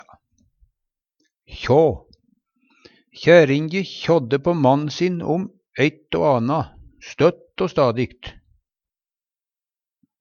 DIALEKTORD PÅ NORMERT NORSK kjå mase Infinitiv Presens Preteritum Perfektum kjå kjår kjådde kjådd Eksempel på bruk Kjærinje kjådde på mann sin om eitt ell anna støtt o stadi.